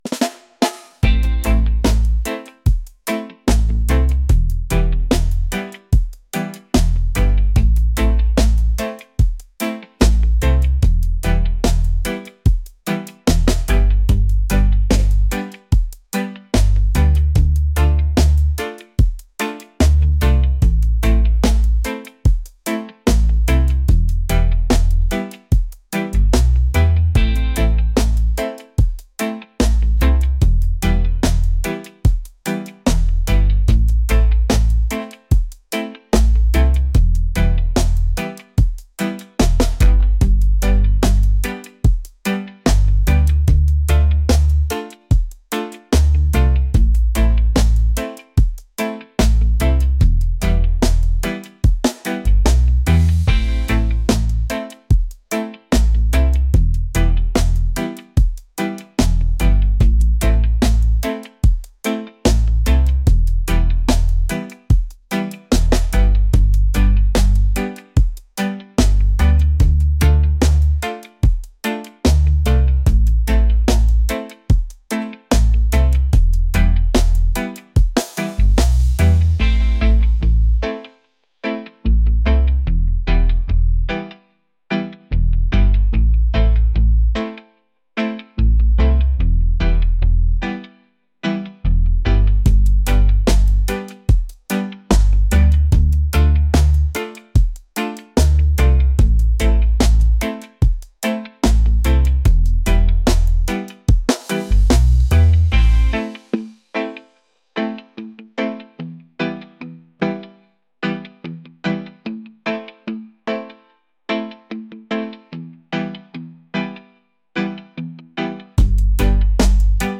reggae | laid-back | groovy